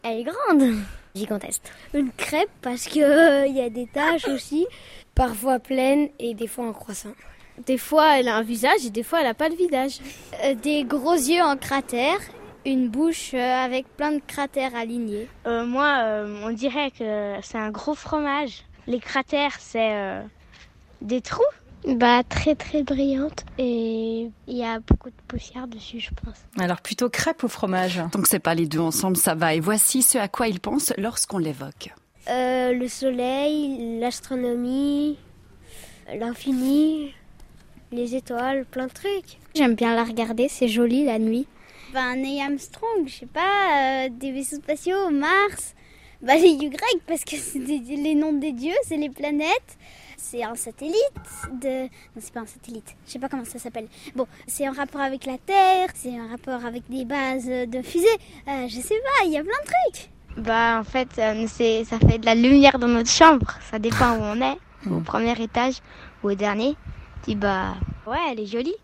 Emission sur la RTS La Première consacrée à la Lune